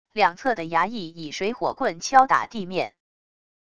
两侧的衙役以水火棍敲打地面wav音频